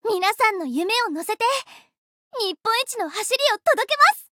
贡献 ） 分类:特别周语音 分类:特别周 您不可以覆盖此文件。